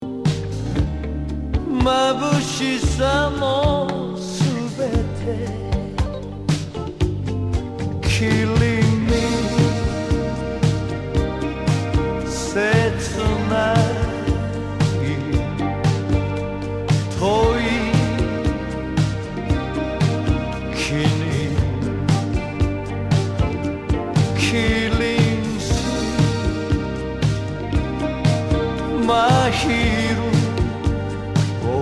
Tag       OTHER ROCK/POPS/AOR